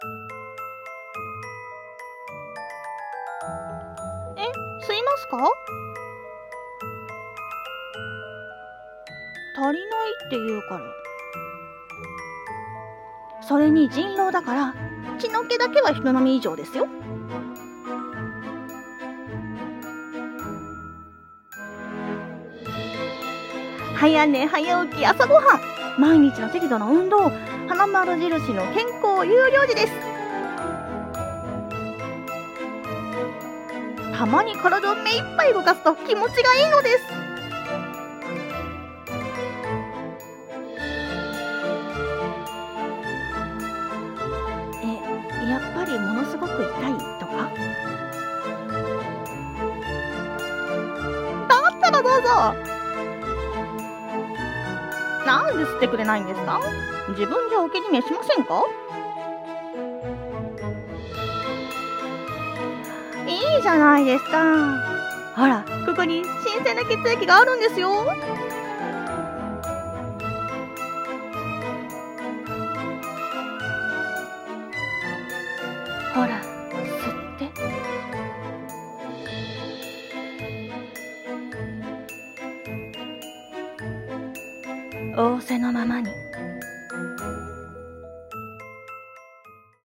】2人声劇『仰せのままに』 ◇